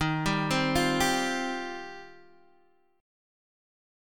Eb6add9 chord